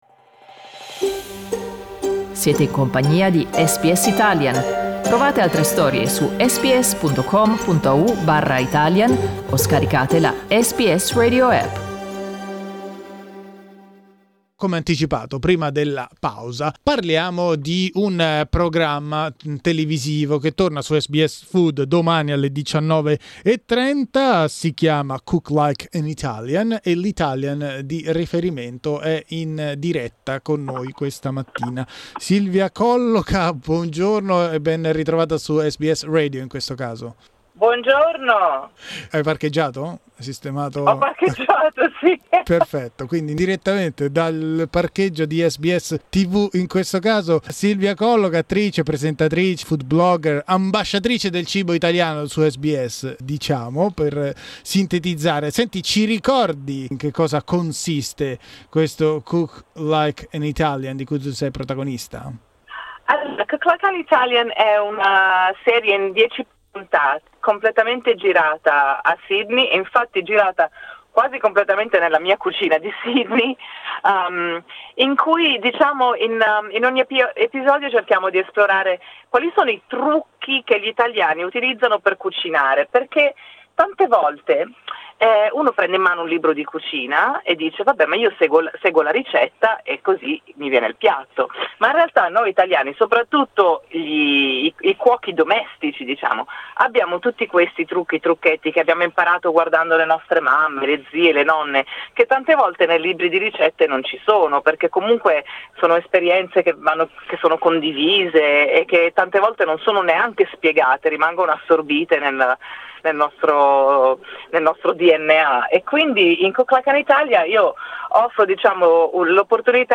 Cook goddess Silvia Colloca tells SBS Italian about her show "Cook like an Italian" on SBS TV.